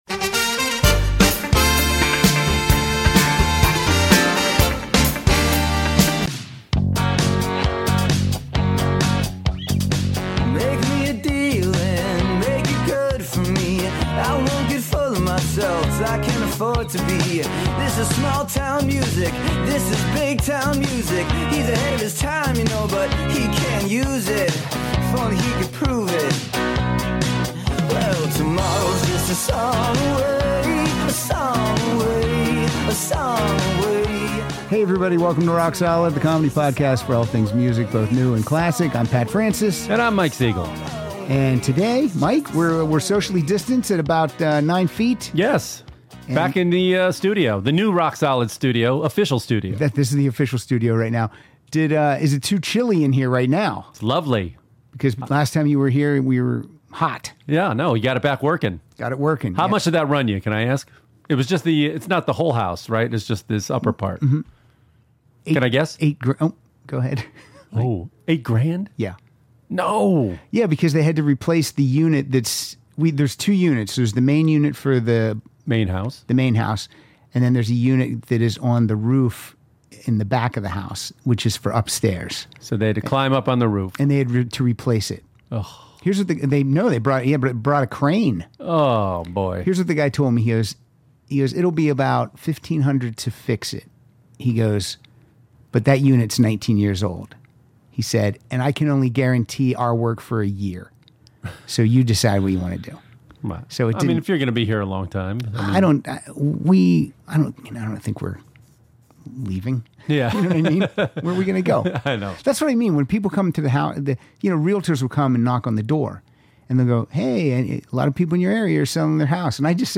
Please enjoy some rock, punk, ska, big band and jazz!